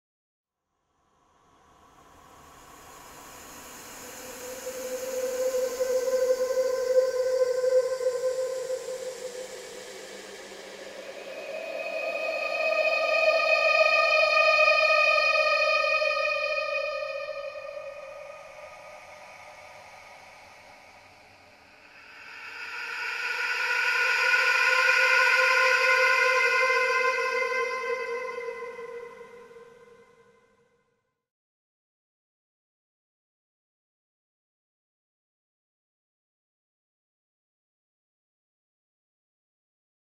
دانلود آهنگ روح 1 از افکت صوتی انسان و موجودات زنده
جلوه های صوتی
دانلود صدای روح 1 از ساعد نیوز با لینک مستقیم و کیفیت بالا
برچسب: دانلود آهنگ های افکت صوتی انسان و موجودات زنده دانلود آلبوم صدای ترسناک روح از افکت صوتی انسان و موجودات زنده